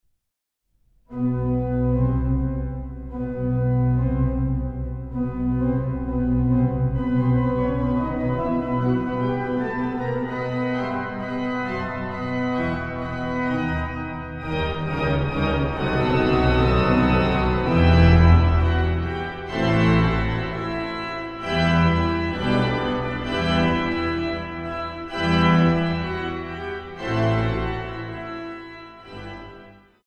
Kern-Orgel in der Frauenkirche Dresden
Orgel
Adagio – Allegro molto